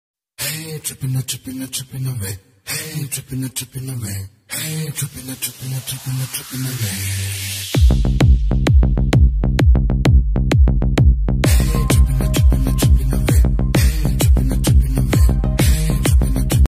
• Simple and Lofi sound
• High-quality audio
• Crisp and clear sound